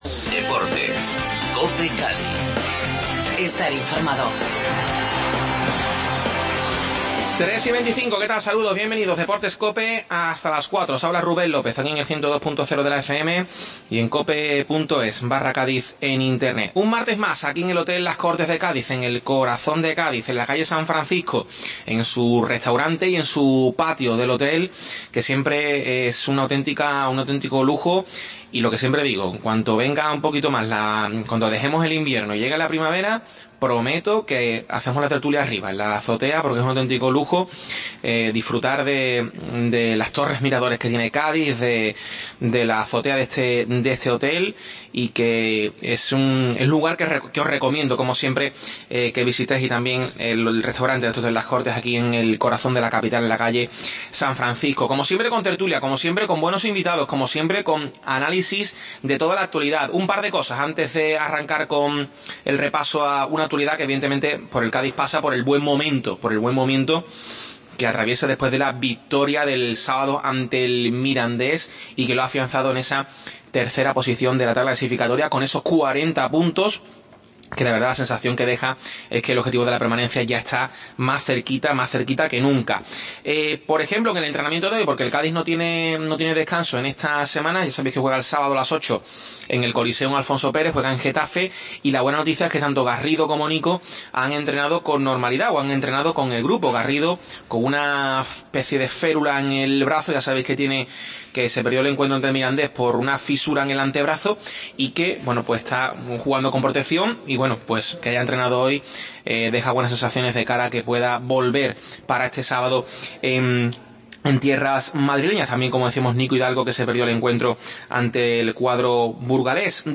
Desde el Hotel Las Cortes tertulia